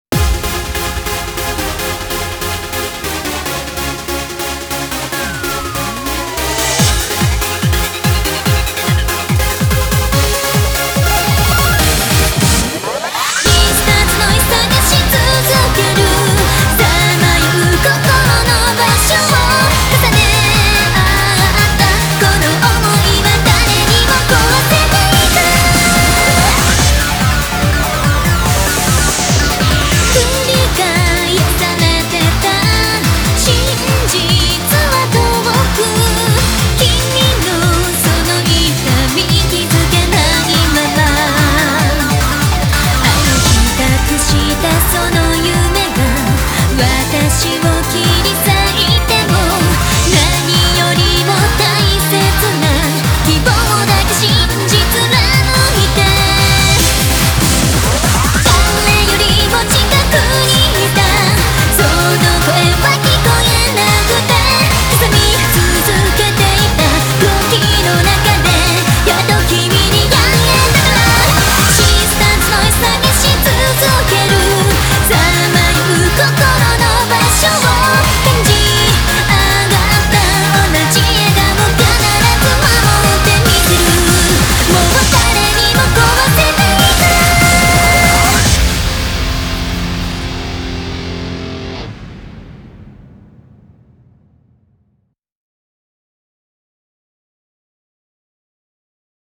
BPM144